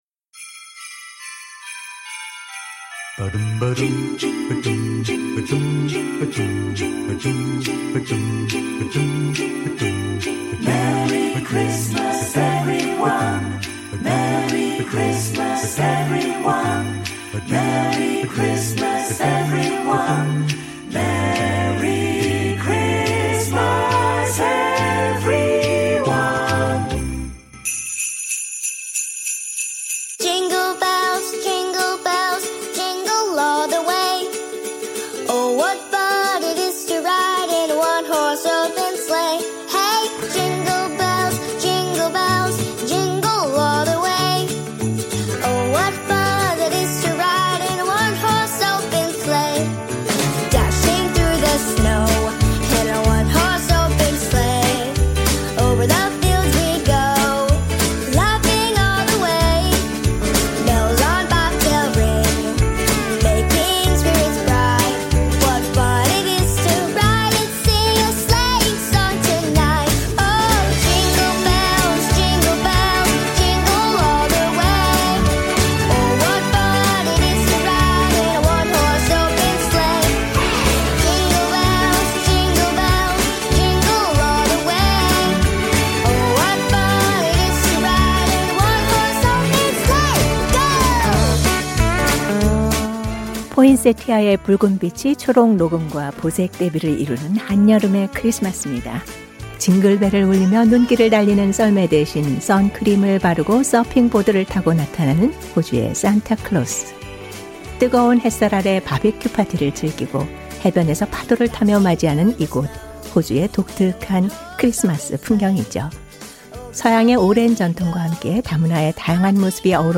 그래도 이곳 스튜디오 언어방송 부서는 평소처럼 하루가 지나가고 있는데요.